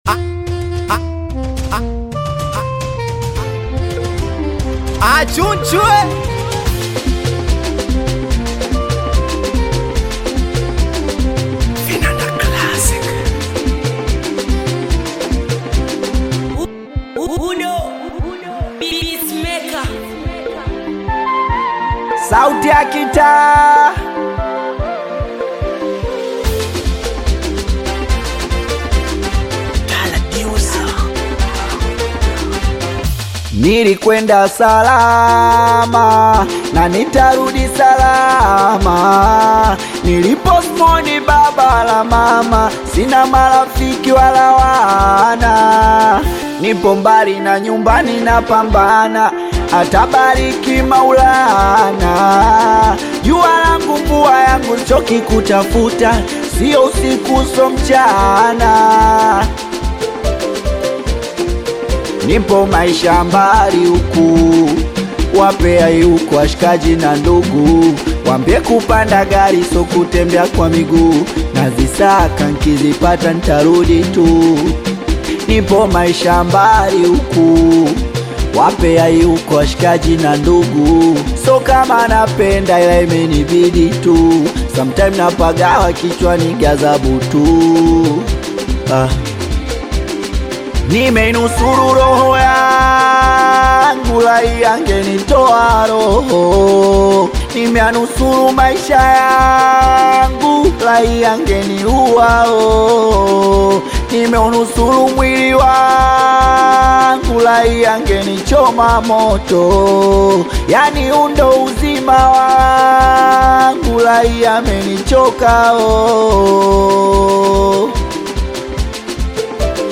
Singeli music track